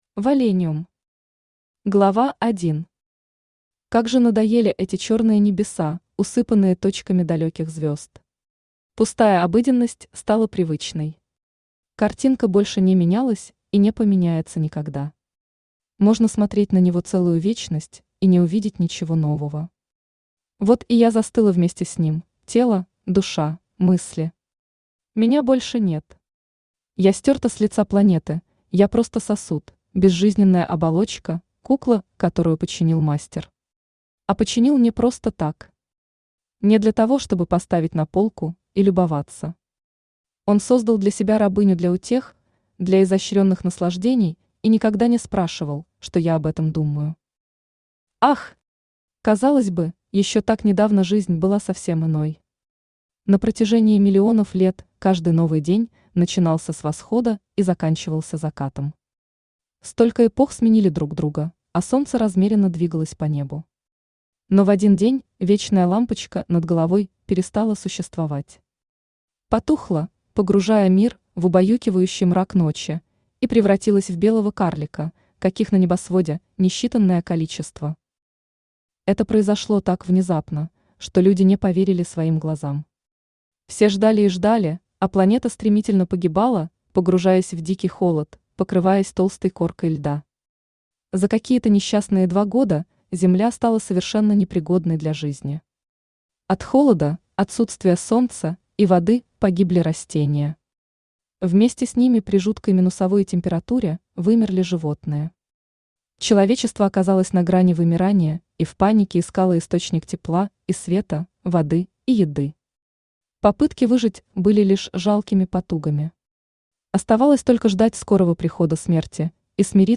Aудиокнига Валениум Автор Юлия Пульс Читает аудиокнигу Авточтец ЛитРес.